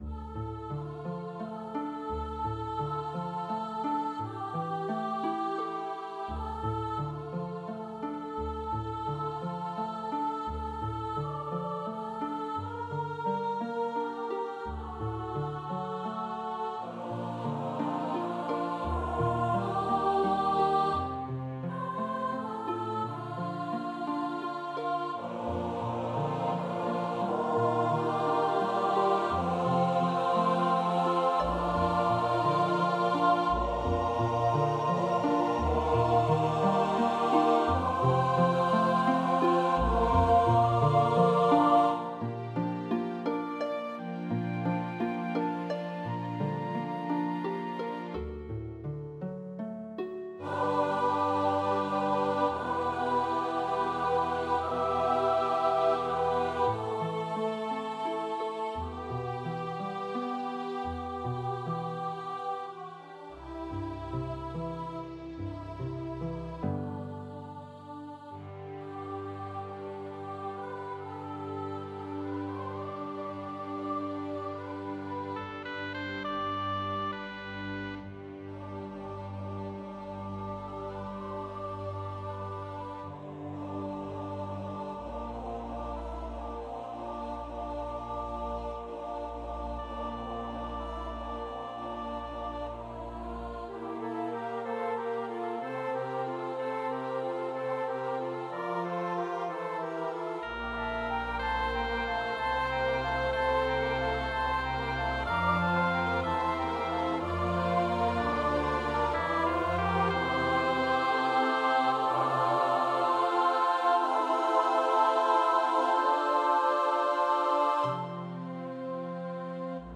This is a lyrical piece for SATB choir.